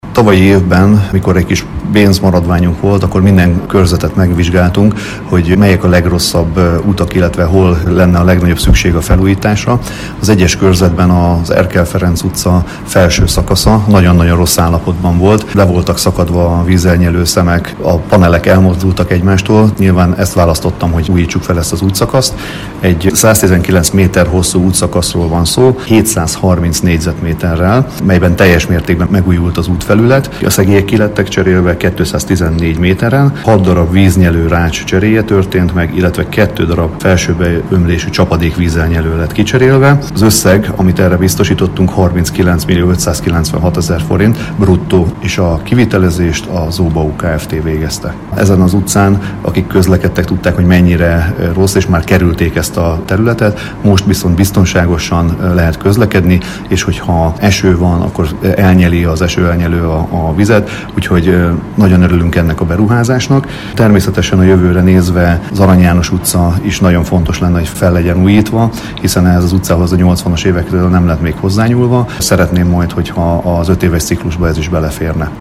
A körzet képviselője, Molnár József nyilatkozott rádiónknak a projekt részleteiről.